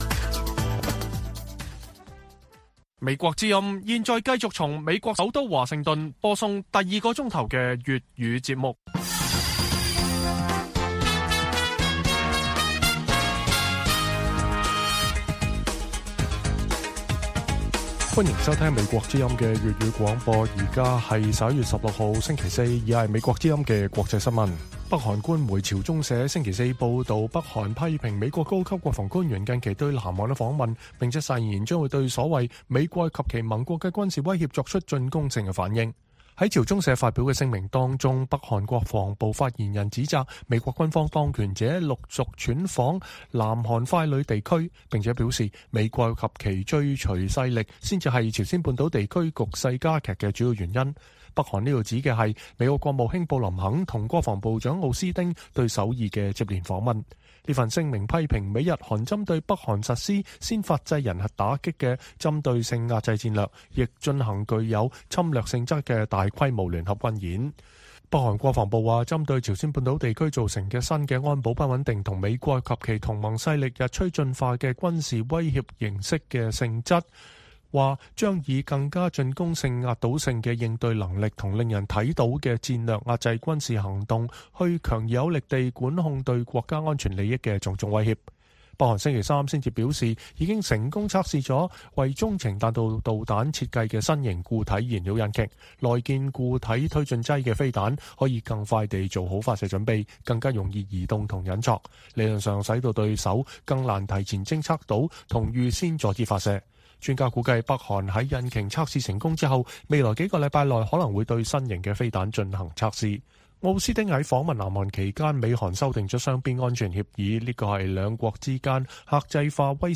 粵語新聞 晚上10-11點: 北韓揚言將針對美國及其盟國的“威脅”做出更具“進攻性”的反應